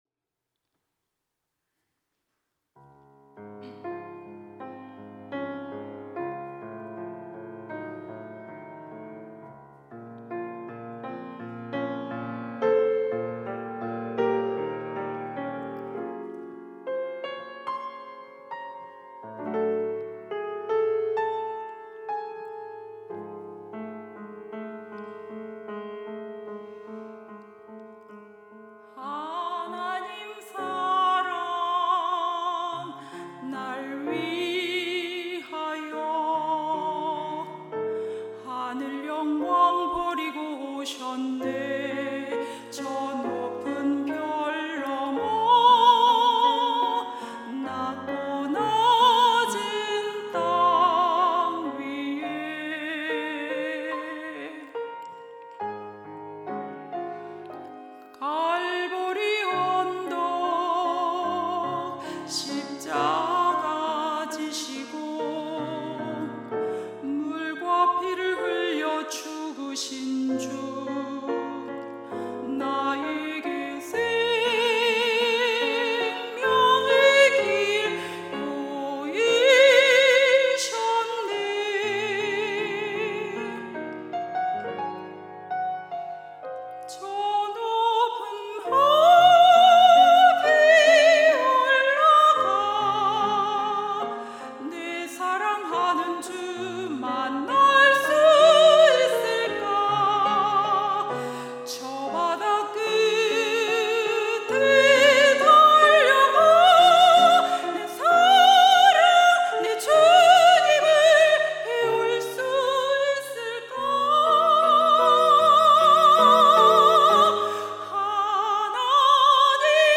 특송과 특주 - Amor Dei (하나님 사랑)